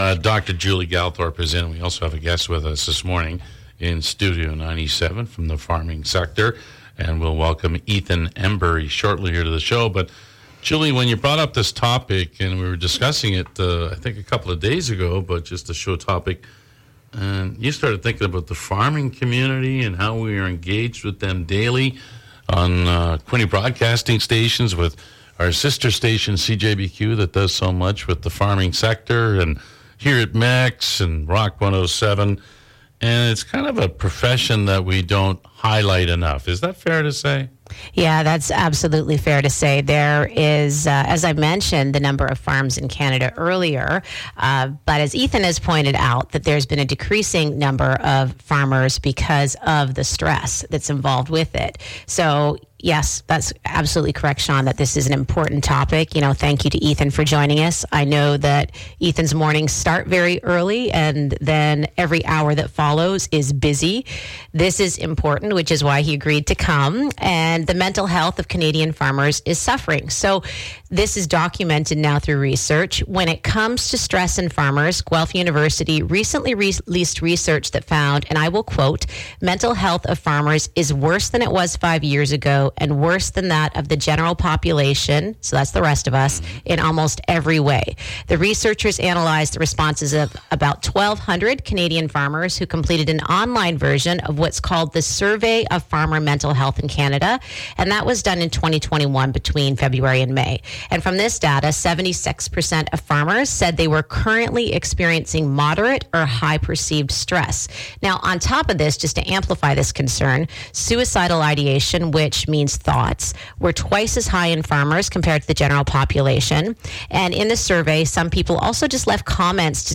with a special guest